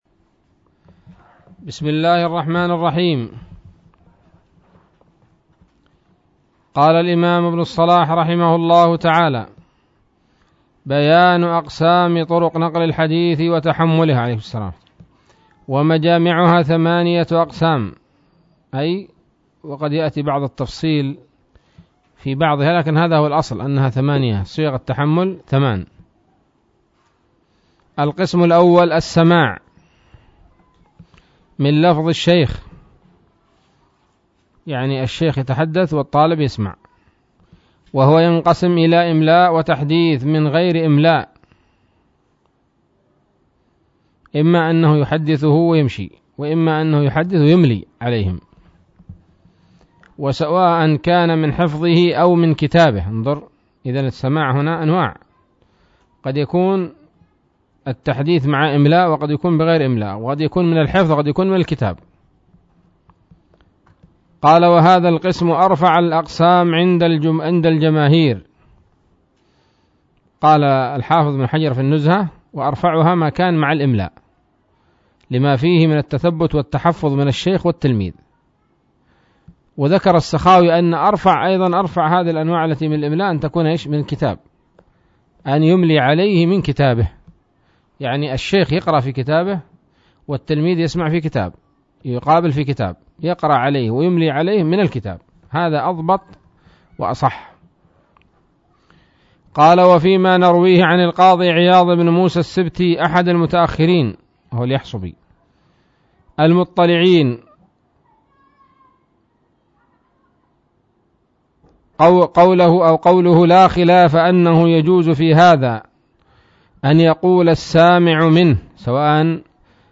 الدرس التاسع والخمسون من مقدمة ابن الصلاح رحمه الله تعالى